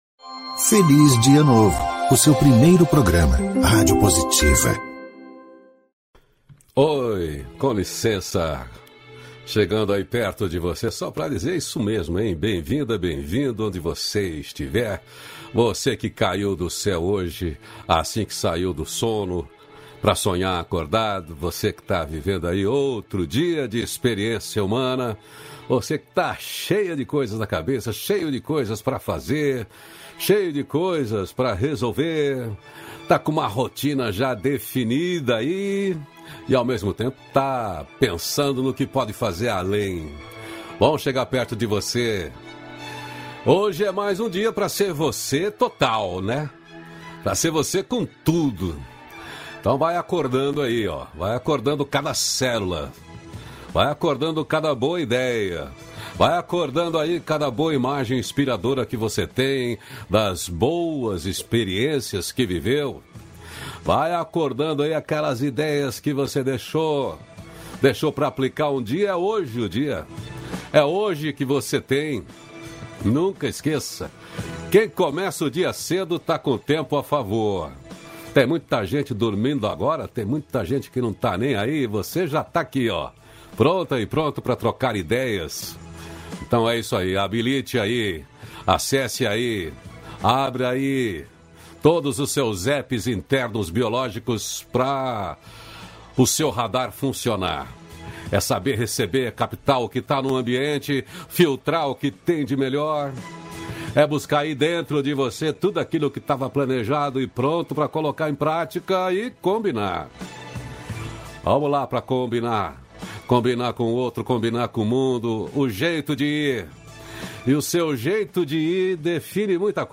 Neste diálogo dois grandes do mundo do conhecimento, das relações, da produtividade e resultado nas empresas e dos eventos corporativos.